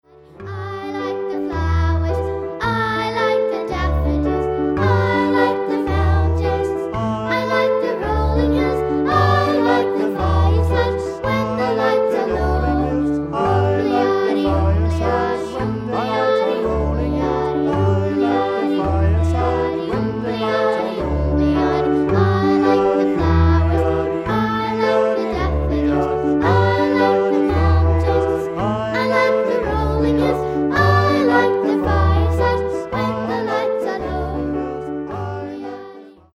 Folk music